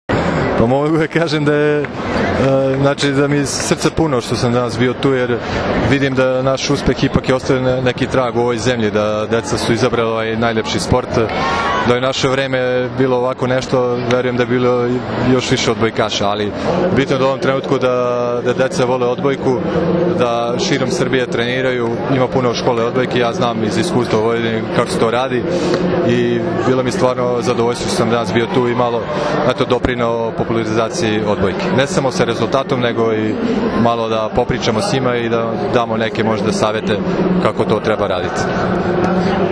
IZJAVA ĐULE MEŠTERA